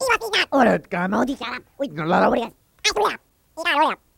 Alien Voices Random, Various Speeds